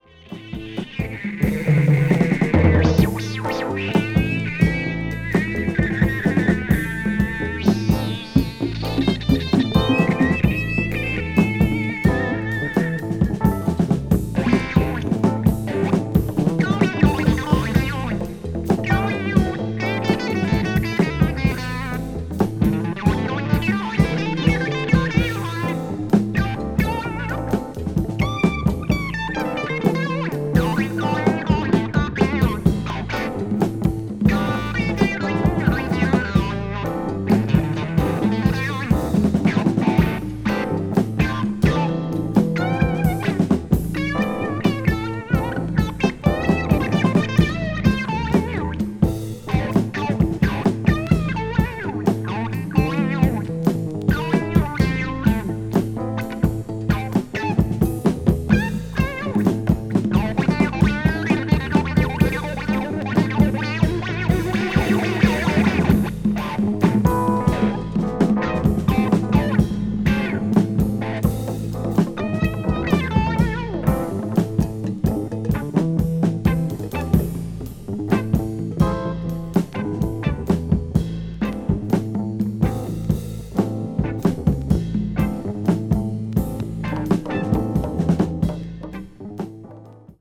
blues rock   crossover   jazz rock   psychedelic rock